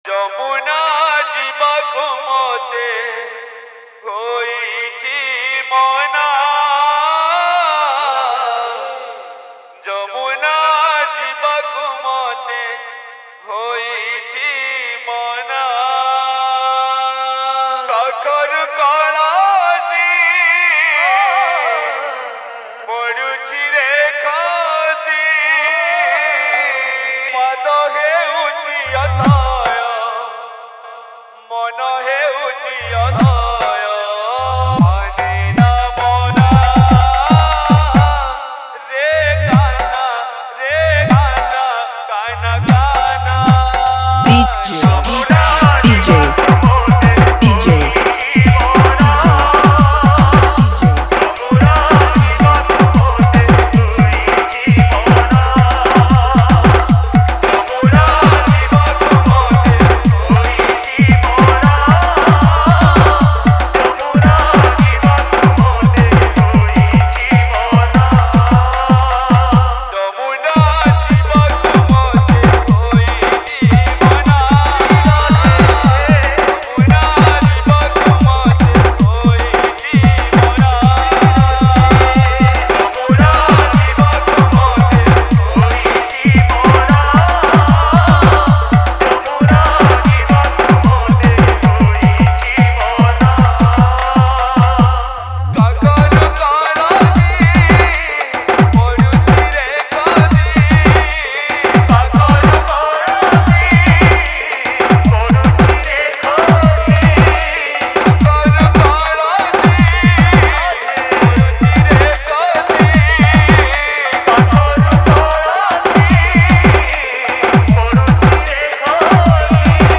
Category:  Odia Bhajan Dj 2022